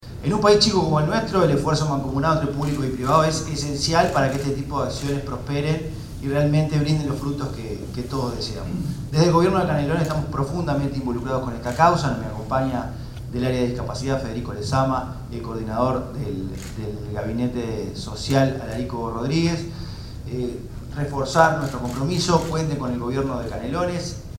francisco_legnani_secretario_general_del_gobierno_de_canelones.mp3